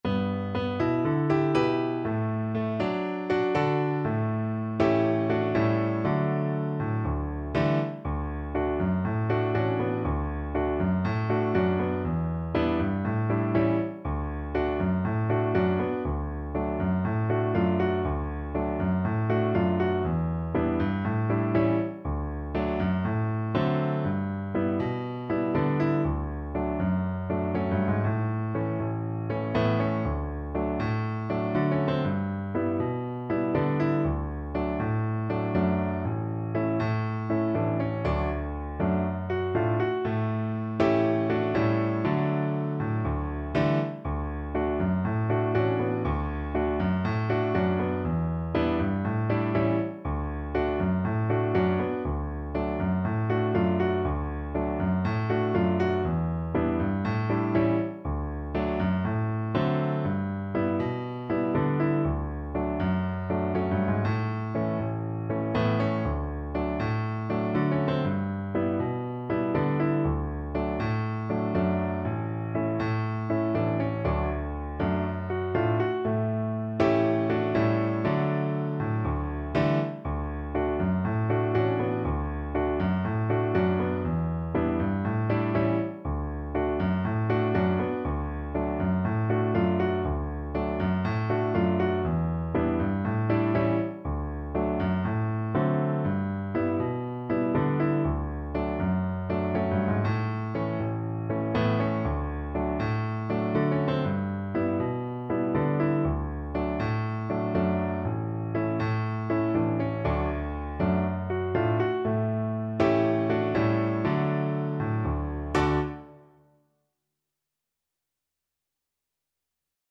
Calypso = 120